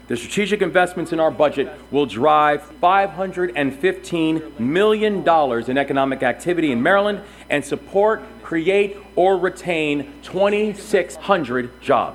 The theme that the Moore Administration is putting forth with its proposed 2026 budget is economic growth in Maryland.  Governor Wes Moore says this budget lays out a growth strategy playing to the state’s economic strengths, including a growing technology sector.